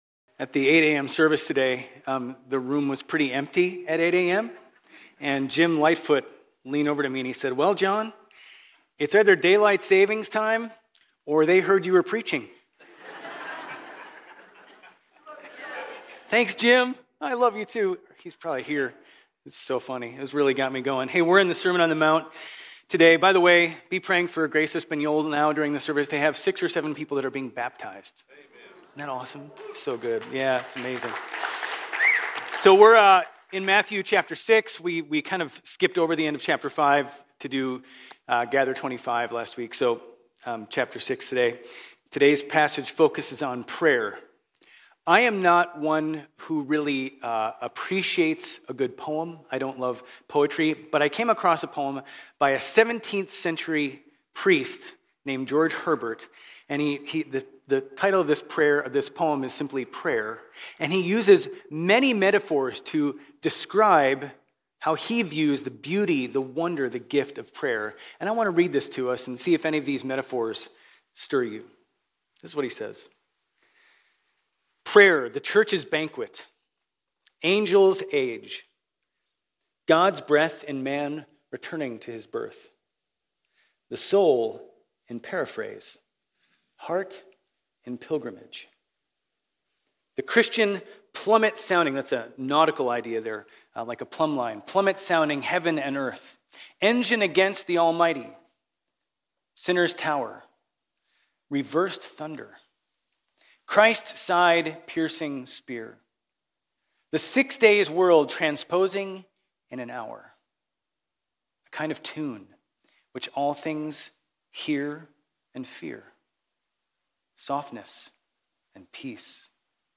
Grace Community Church Old Jacksonville Campus Sermons 3_9 Old Jacksonville Campus Mar 10 2025 | 00:35:40 1x 00:00 / 00:35:40 Subscribe Share RSS Feed Share Link Embed